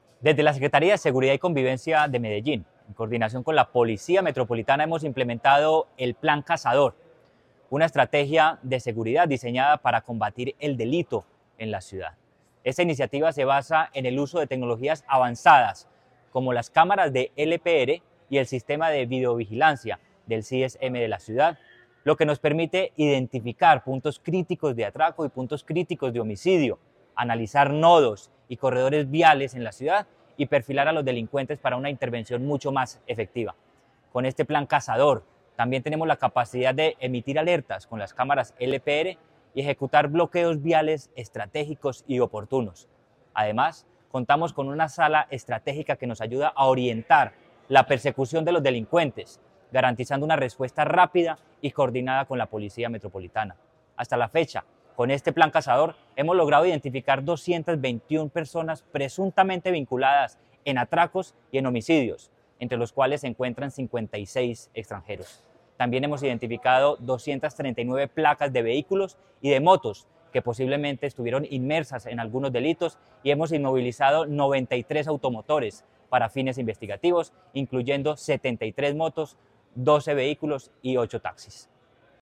Palabras de Manuel Villa Mejía, secretario de Seguridad y Convivencia de Medellín